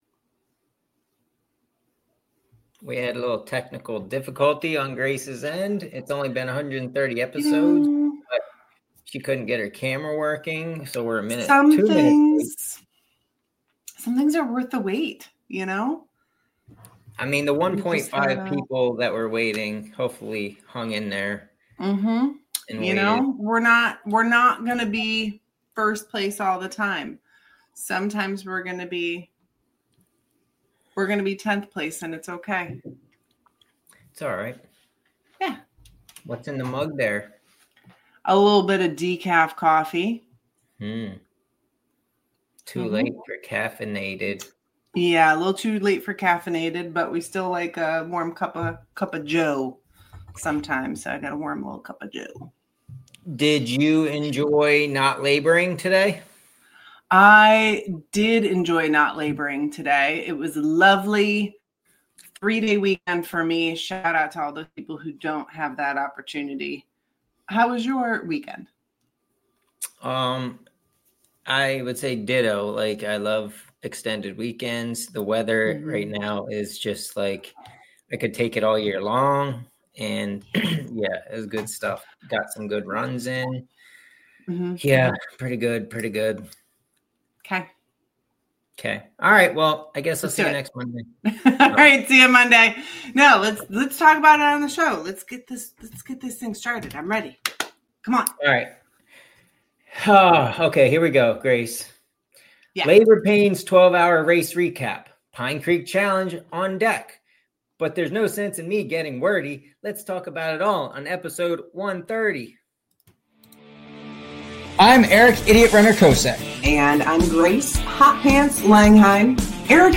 No script, just us. It’s Labor Day and we’re putting in the work the only way we know how—by talking miles, mayhem, and whatever else pops into our brains.